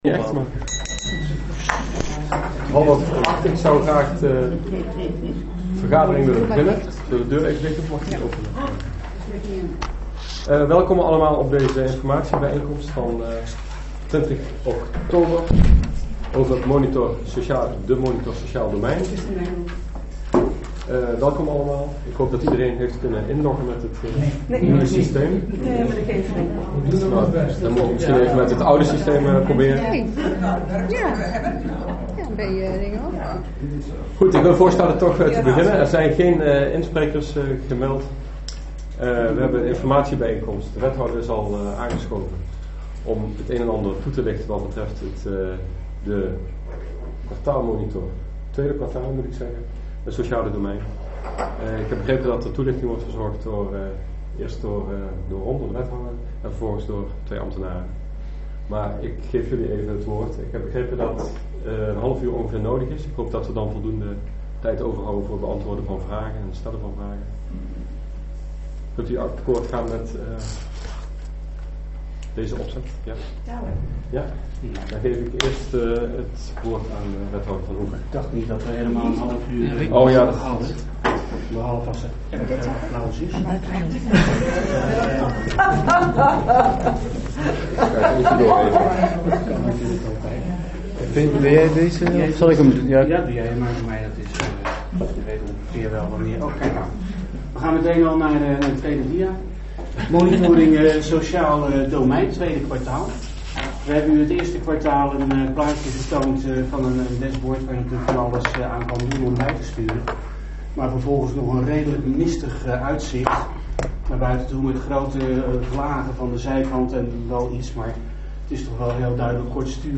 Locatie S02, gemeentehuis Elst
Informatiebijeenkomst monitor sociaal domein